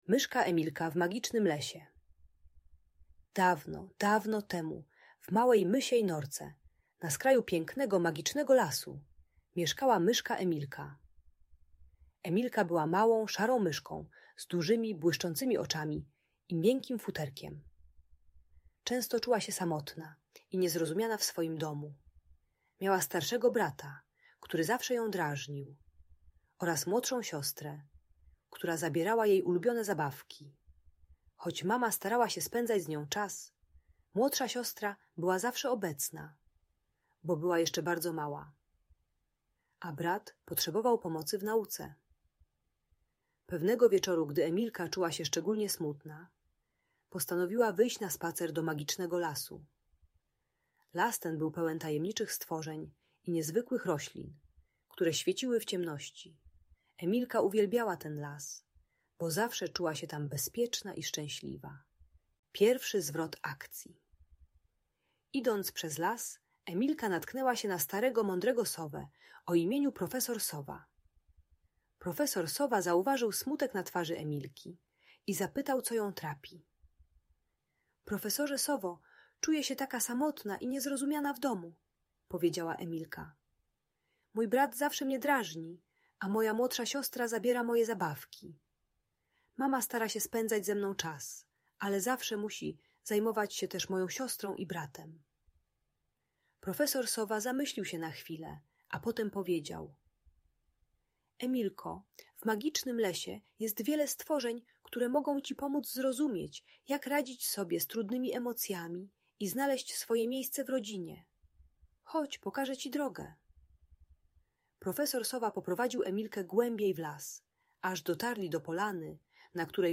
Magiczna opowieść o Myszce Emilce - Rodzeństwo | Audiobajka